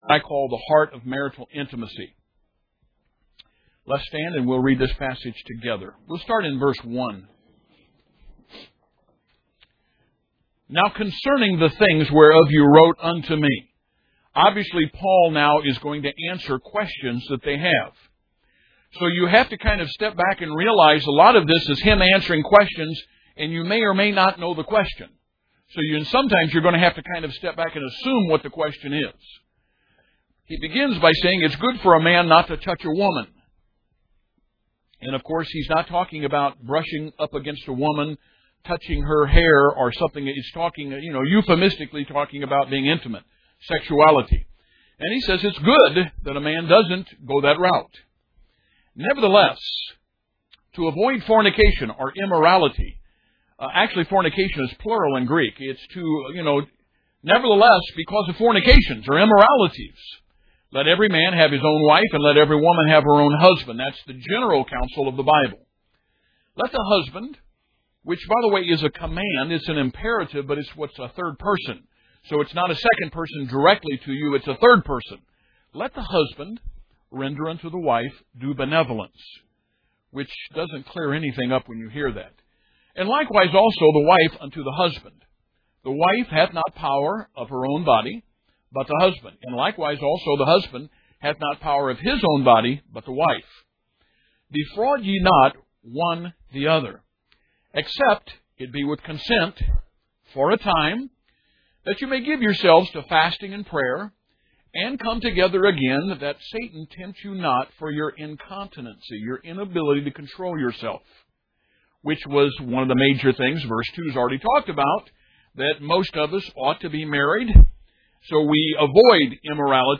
Service: Sunday School